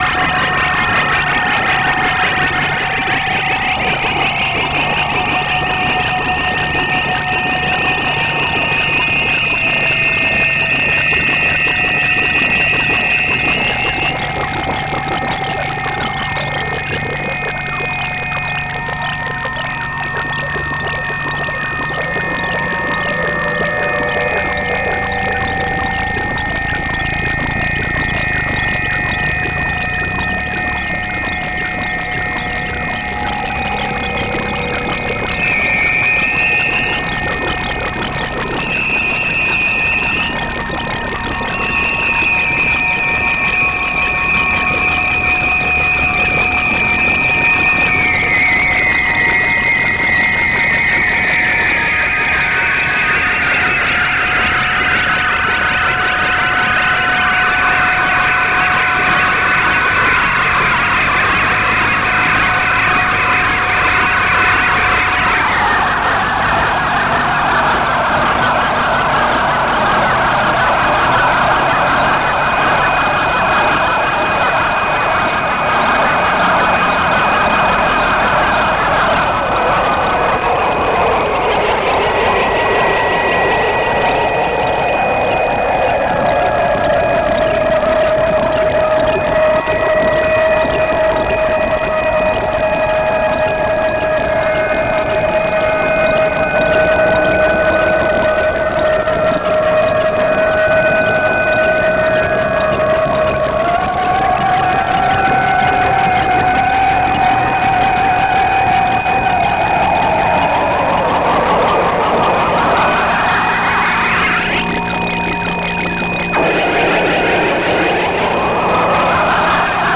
• Genre: Experimental / Musique Concrete